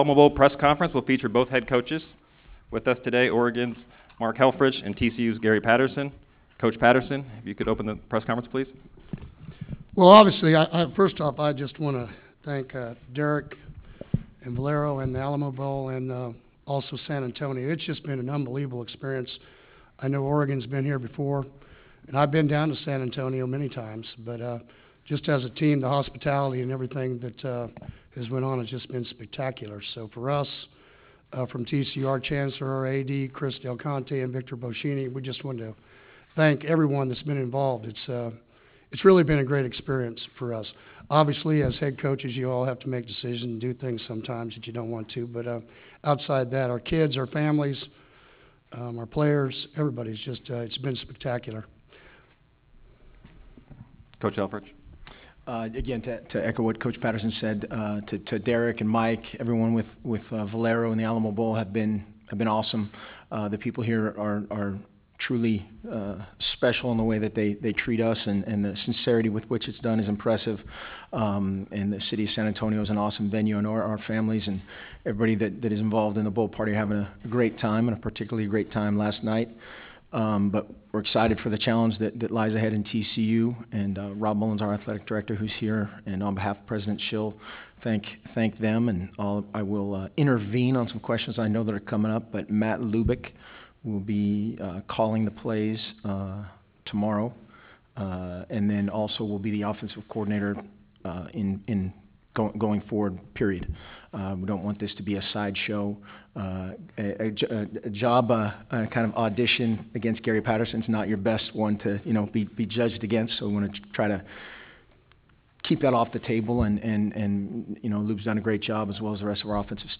Valero Alamo Bowl Head Coaches Press Conference
Oregon’s Mark Helfrich and TCU’s Gary Patterson speak the day before the big game.
ABowl-2016-Coaches-PC-Jan-1.wav